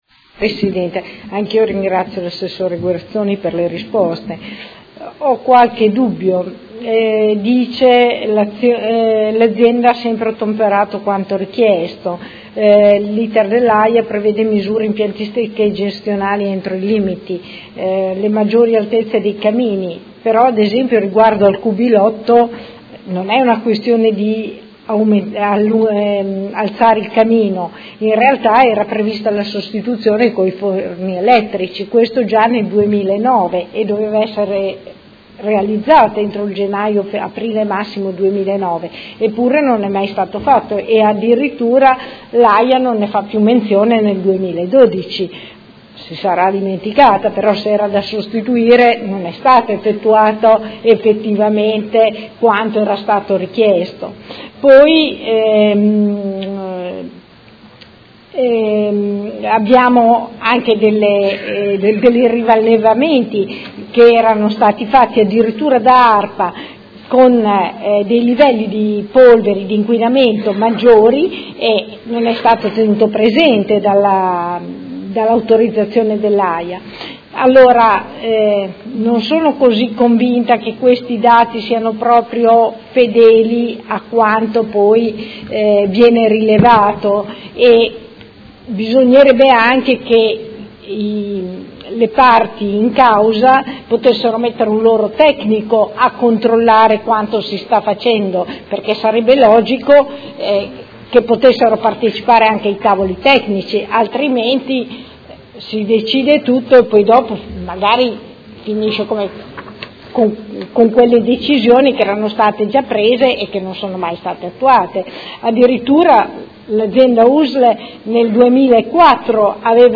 Seduta del 18/05/2017. Conclude interrogazione della Consigliera Santoro del Gruppo IDEA – Popolo e Libertà avente per oggetto: Fonderie Cooperative di Modena S.C. AR.L. nel Quartiere Madonnina, inquinamento dell’ambiente